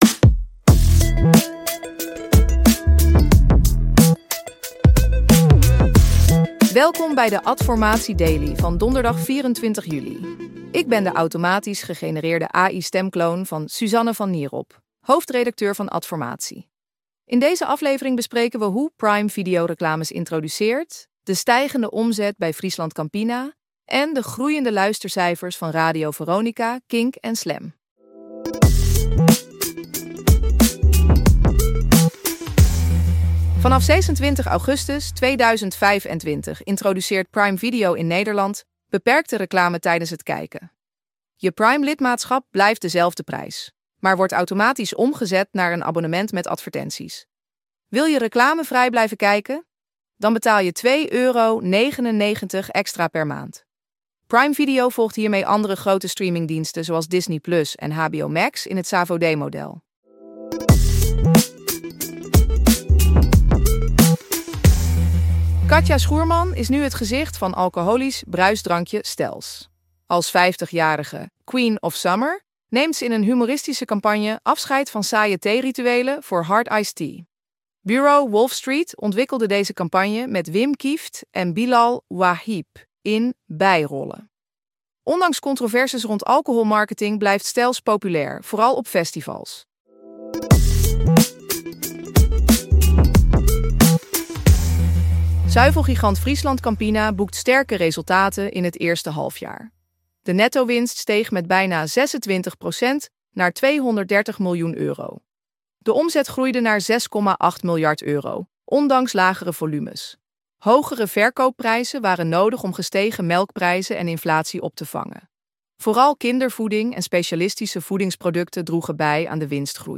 Geïnteresseerd in een automatisch gegenereerde podcast voor jouw content?